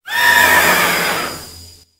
Cri de Spectreval dans Pokémon Épée et Bouclier.